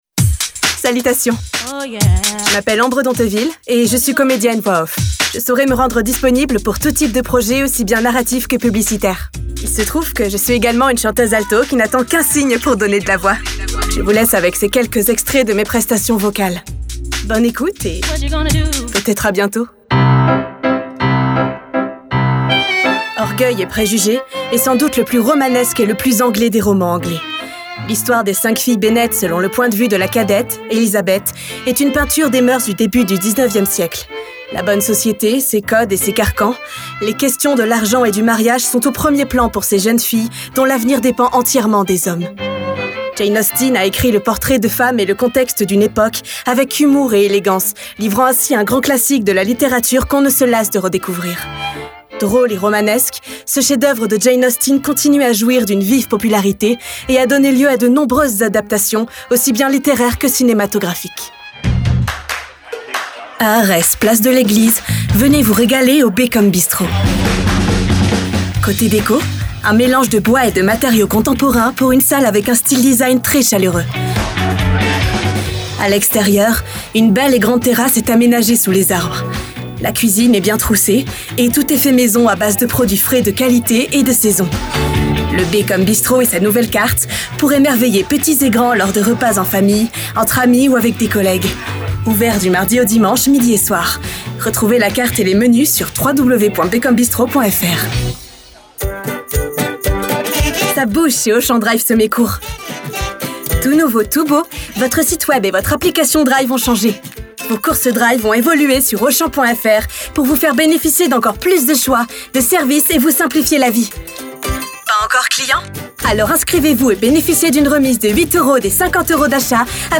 Voix off
Bande Démo 2020
Chanteur(euse)